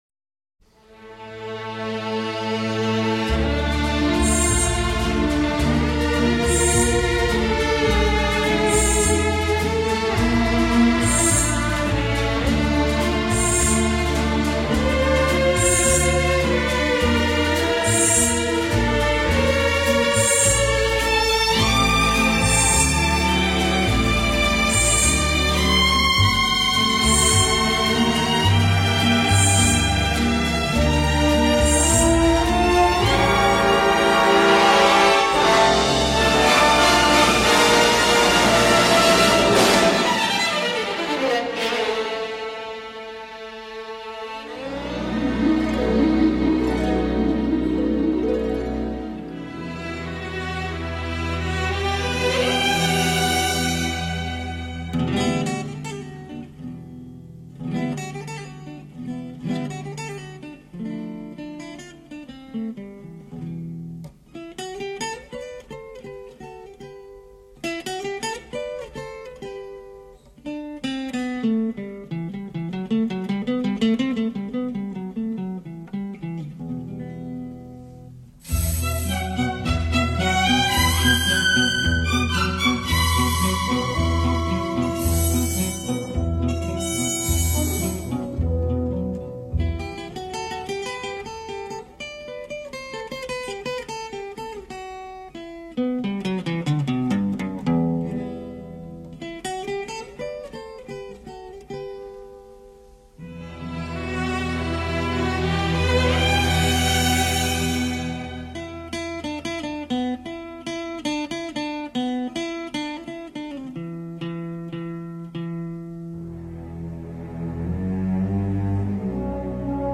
lead guitar